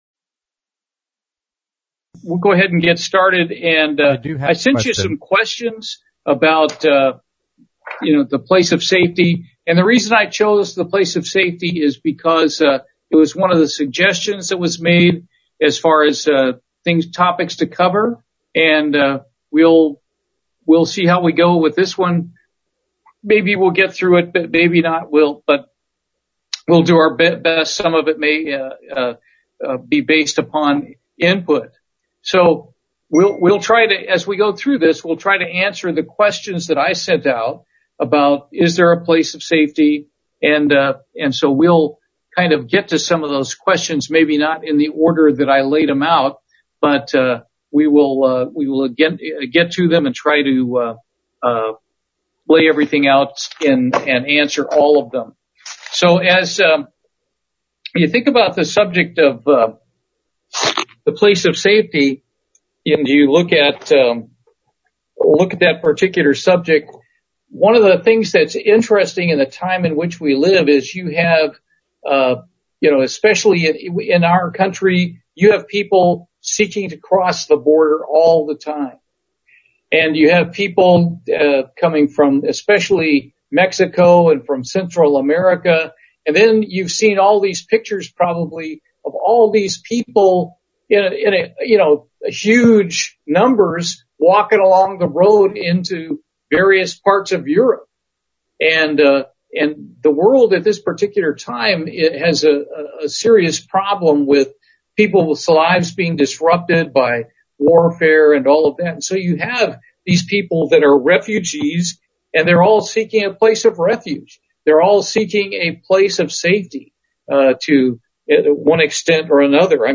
Print This Bible study is the first of a two part series on the place of safety.